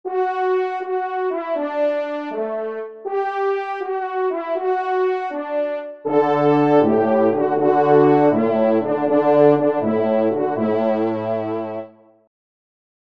Genre : Fantaisie Liturgique pour quatre trompes
Pupitre de Basse